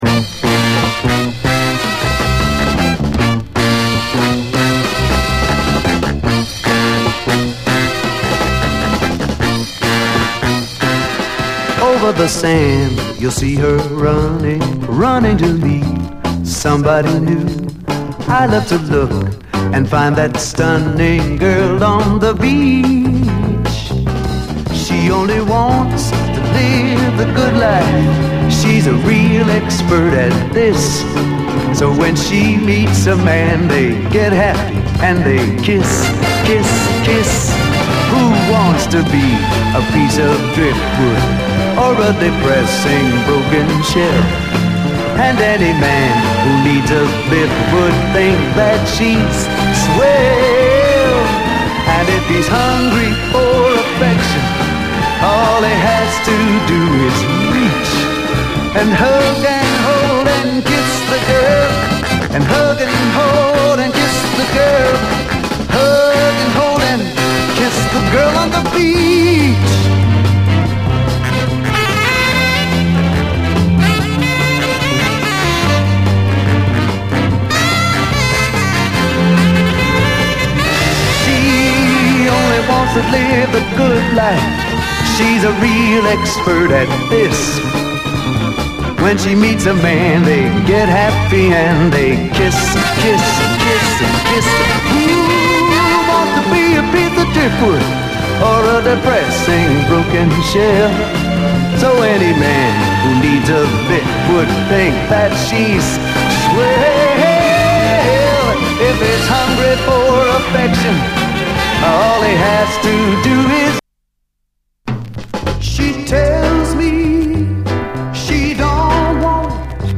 SOUL, 60's SOUL, 70's～ SOUL, 7INCH
流麗なストリングス・アレンジがゴージャスなムードを演出！
どちらも流麗なストリングス・アレンジがゴージャスなムードを演出します。